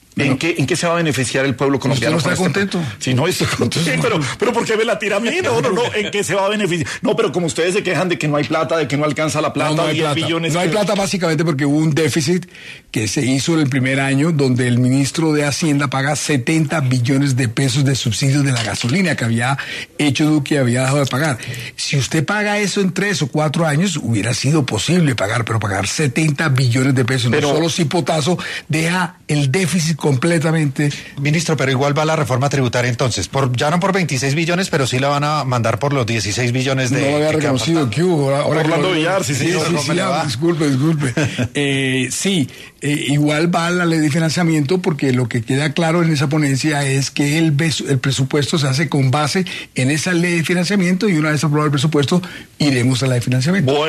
En ‘Sin Anestesia’ de ‘La Luciérnaga’, estuvo el Ministro del Interior, Armando Benedetti, para abordar qué pasará con la Reforma Tributaria.